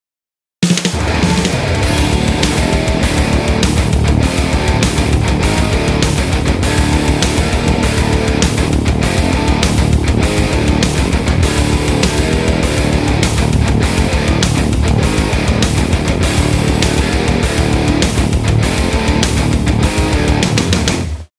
Господа, как обладатель Хафлера Бонера хочу отметить, что звук не такой, бонер звучит собраннее, перегруз на примерах какой то ...